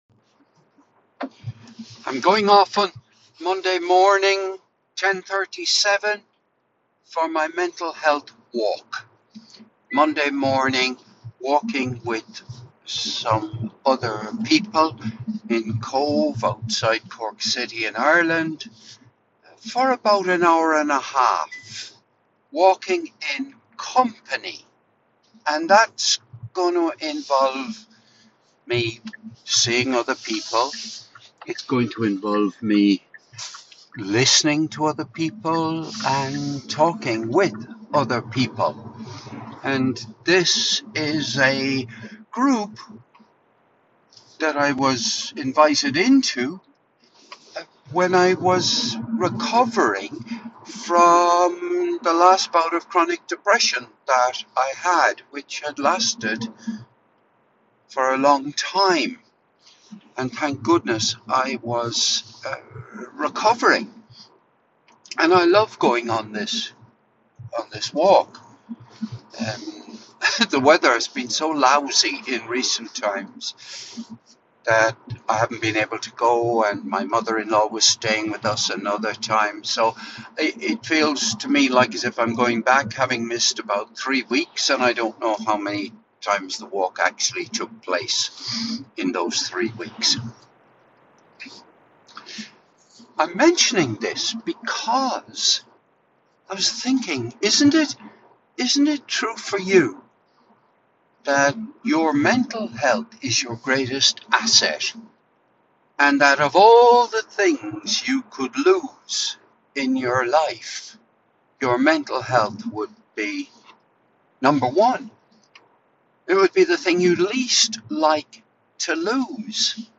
This was recorded while driving from Cork to Limerick on Monday 30th December 2024